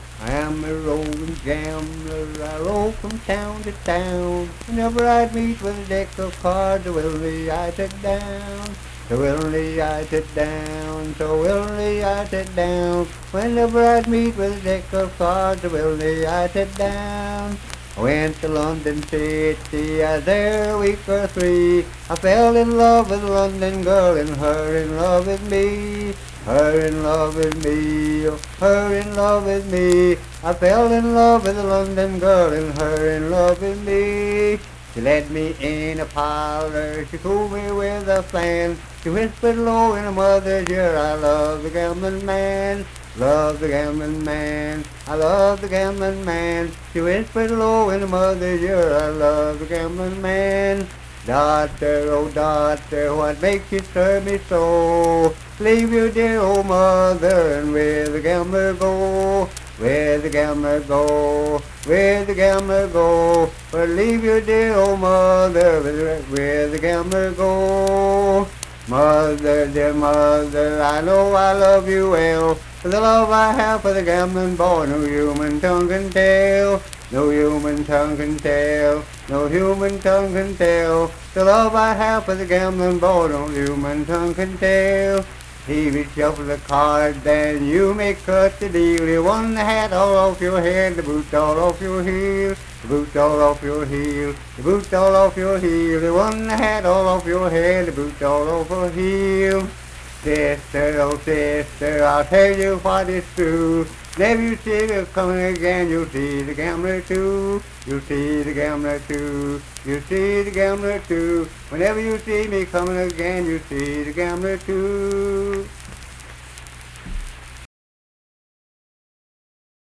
Unaccompanied vocal and banjo music
Voice (sung)